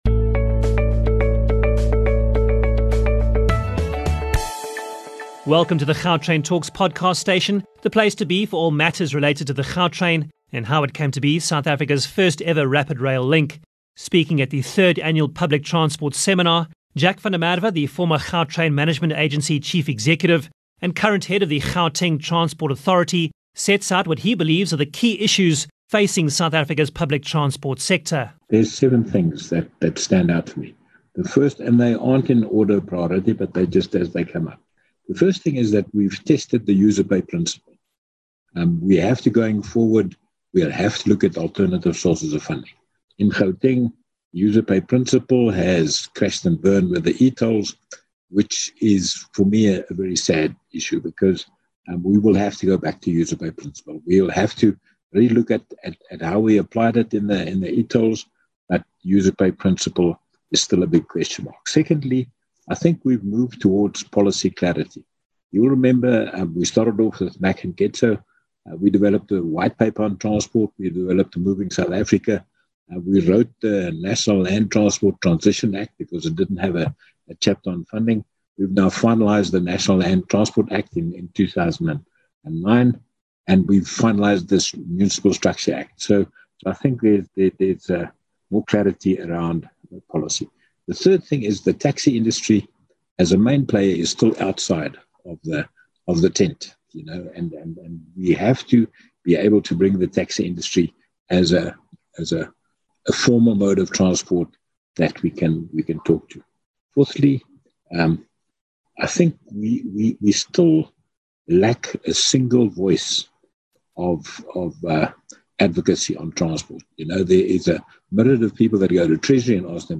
Speaking at the third annual public transport seminar, Jack van der Merwe, the former Gautrain Management Agency chief executive and current head of the Gauteng Transport Authority, sets out what he believes are the key issues facing South Africa’s public transport sector, from a governance point of view.